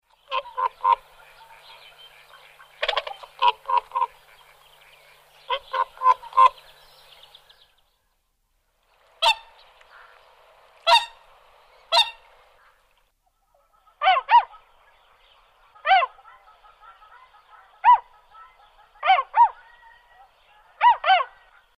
Звуки лебедей
Вы можете слушать или скачать их голоса, шум крыльев и плеск воды в высоком качестве.